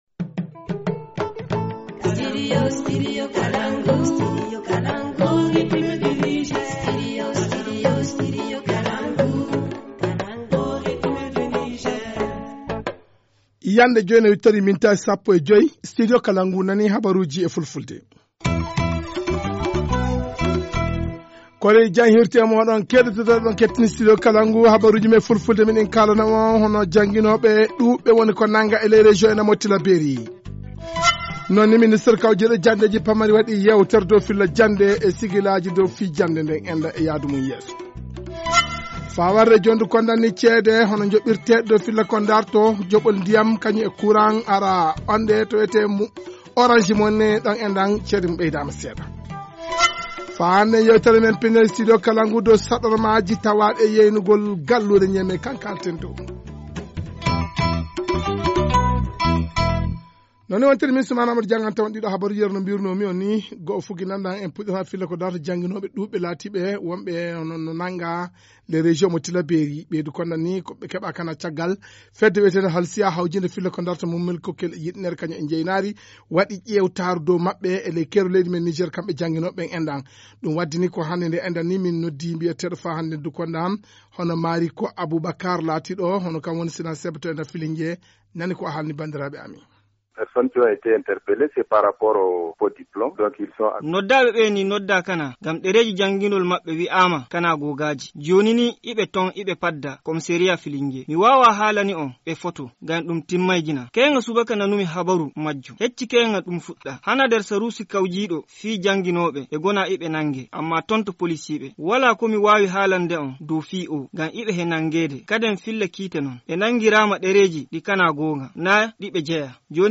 1. Vague d’arrestations des enseignants du primaire dans la région de Tillabery pour détention de faux diplômes. Les responsables du syndicat national des agents contractuels et fonctionnaires de base SYNACEB de Fillingué et Say se prononcent dans ce journal.
Journal en français